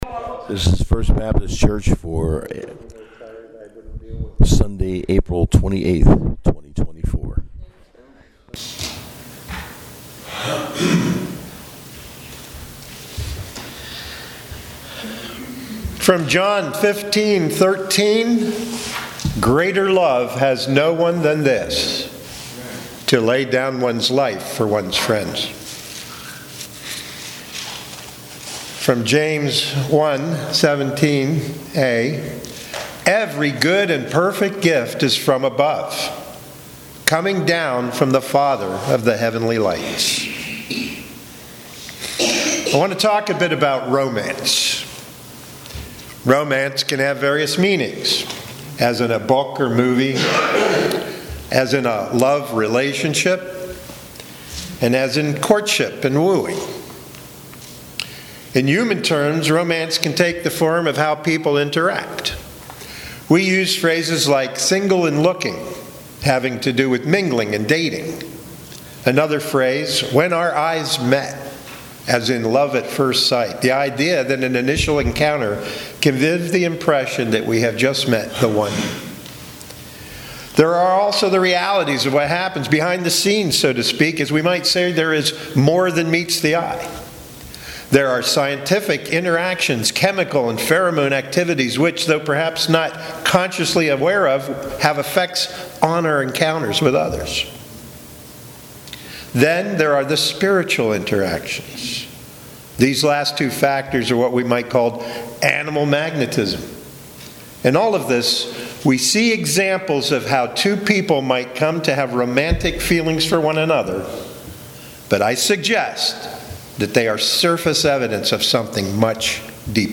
Sunday Sermon on John 15:13 and James 1:17